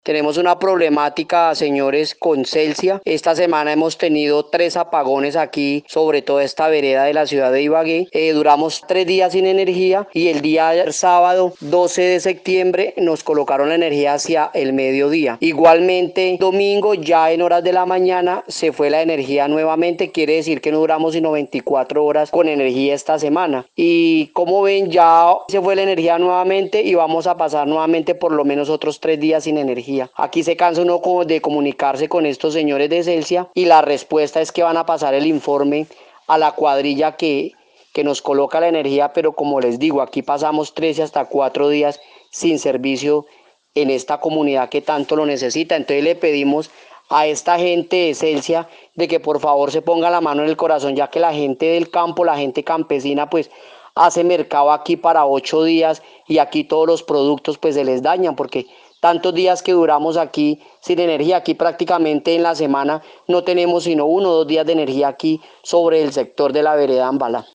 OYENTE SE QUEJA POR LOS REPETIDOS CORTES DE ENERGÍA DURANTE LA SEMANA, PIDE A CELSIA REACCIÓN INMEDIATA EN LA VEREDA AMBALA
Radio